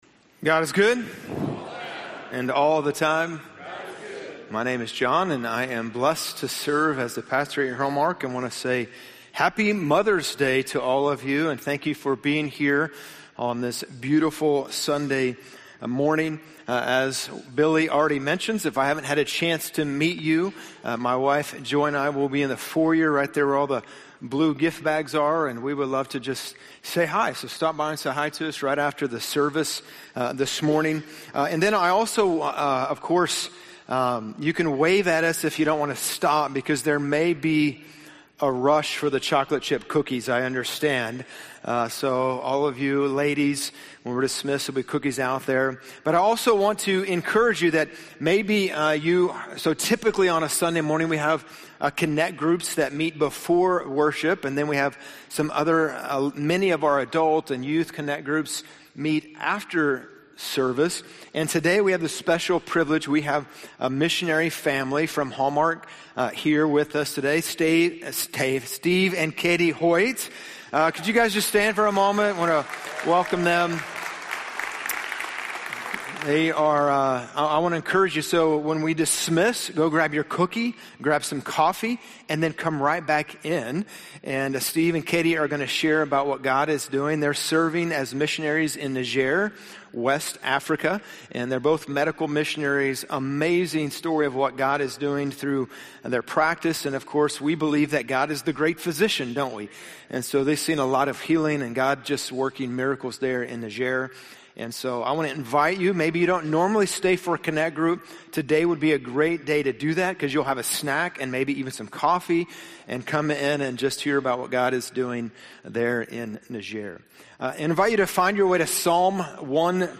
Missing Peace #4 - Shalom in the Home - Sermons - Hallmark Church